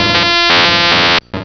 Cri d'Élektek dans Pokémon Rubis et Saphir.